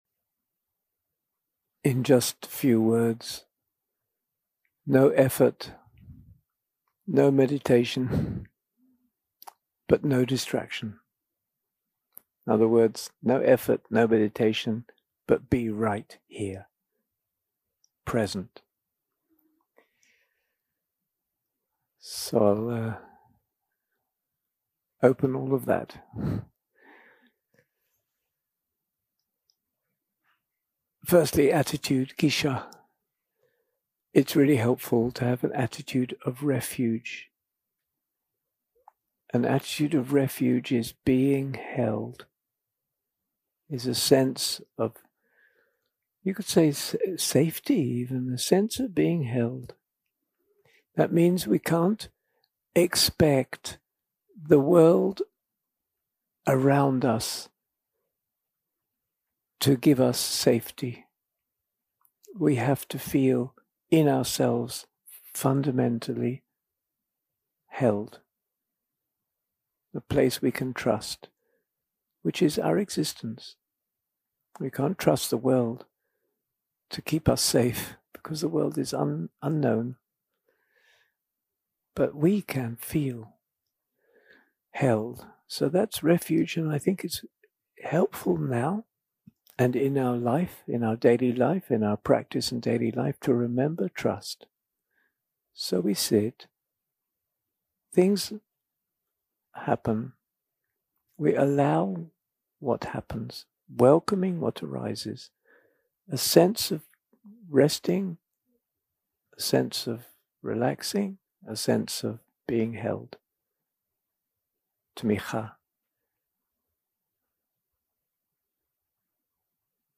יום 3 – הקלטה 4 – בוקר – הנחיות למדיטציה – הנחיות כלליות למדיטציית אי-שניות 0:00 0:00 סוג ההקלטה: Dharma type: Guided meditation שפת ההקלטה: Dharma talk language: Hebrew